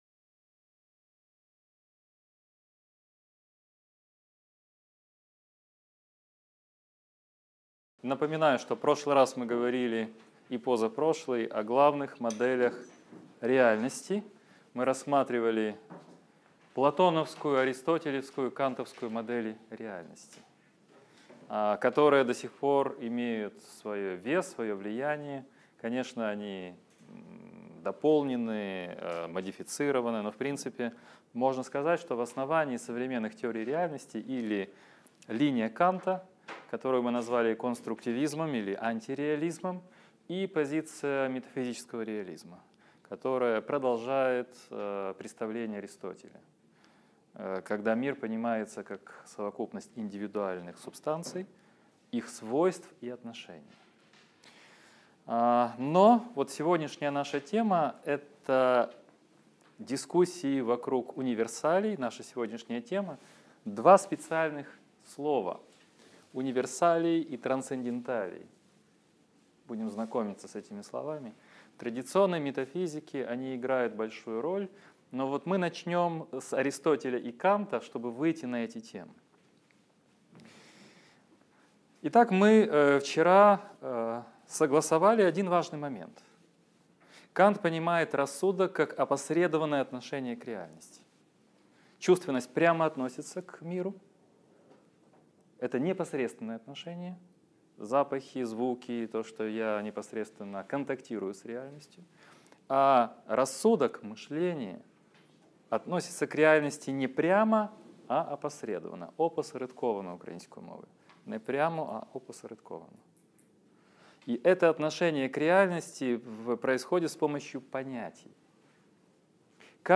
Аудиокнига Лекция 13. Грамматика метафизики: универсалии, категории, трансценденталии | Библиотека аудиокниг